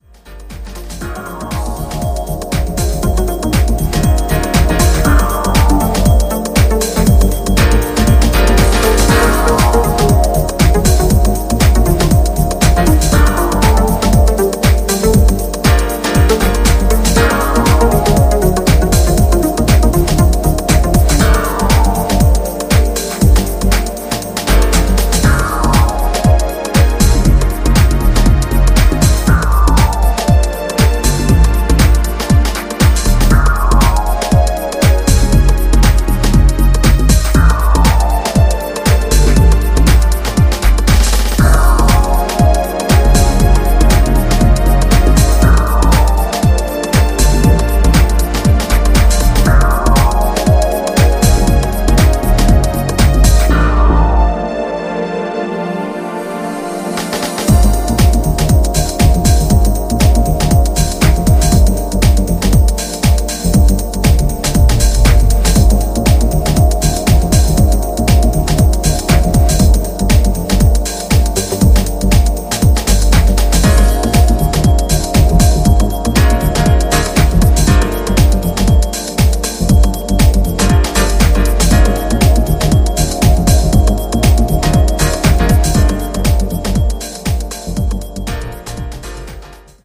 90s UK deep house bliss